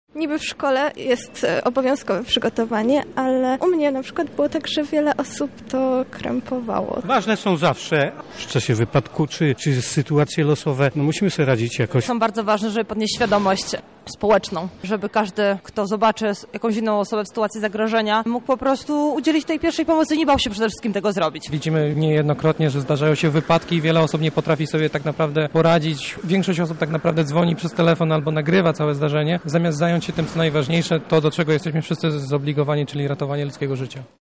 Zapytaliśmy podróżnych co sądzą o takich szkoleniach.